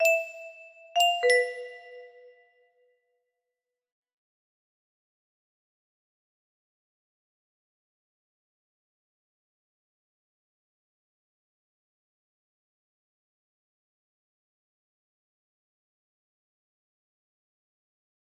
Unknown Artist - Untitled music box melody
Grand Illusions 30 music boxes More